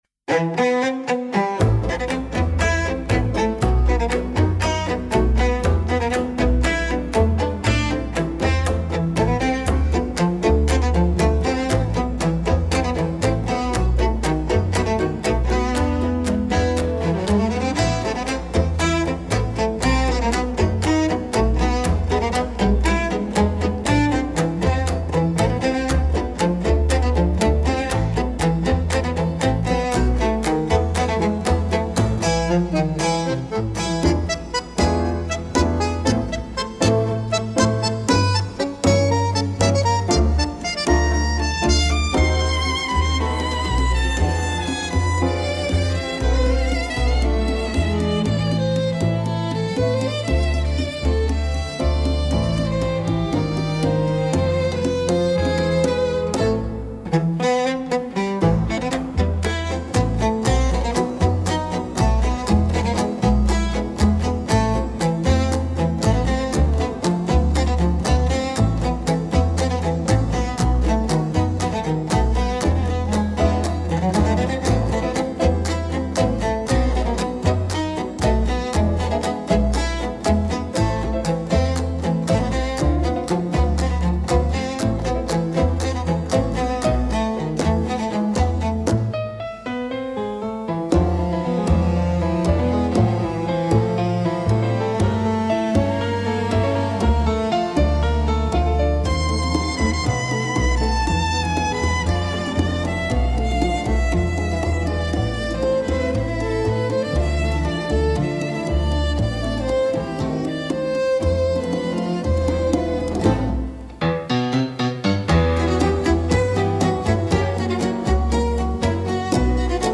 música, arranjo: IA) (instrumental)